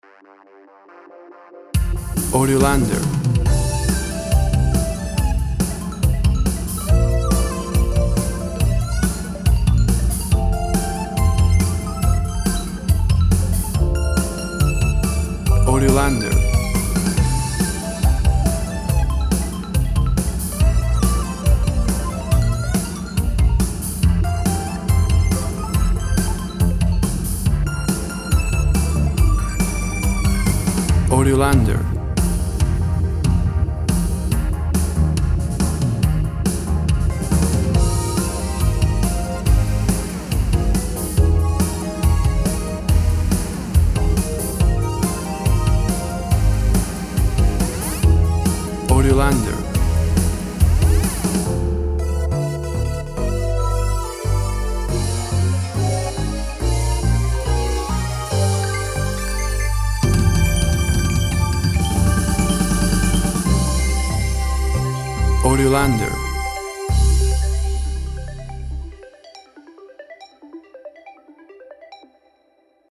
Upbeat synth melody and powerful beat.
Tempo (BPM) 140